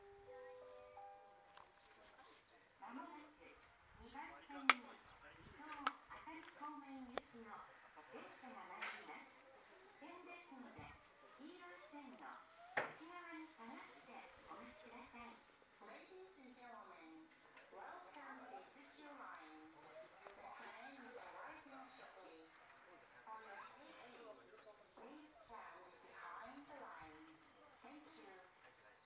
２番線IZ：伊豆急行線
接近放送普通　伊東行き接近放送です。音量が小さいです。